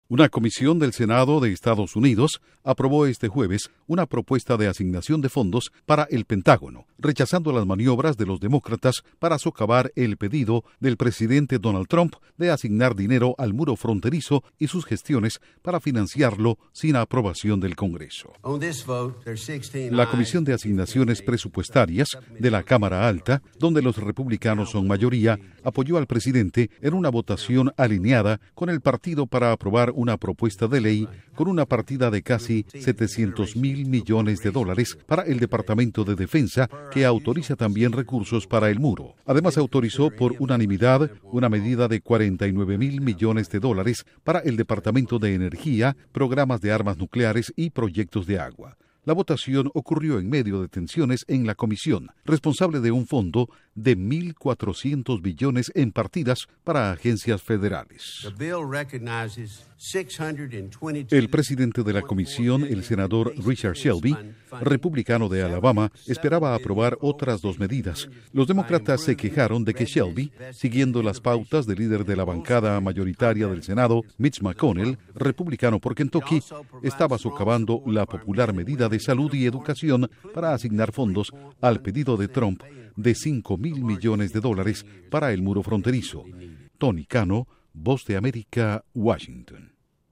Duración: 1:30 Incluye reacción de senadores demócratas